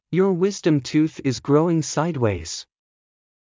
ﾕｱ ｳｨｽﾞﾀﾞﾑ ﾄｩｰｽ ｲｽﾞ ｸﾞﾛｳｲﾝｸﾞ ｻｲﾄﾞｳｪｲｽﾞ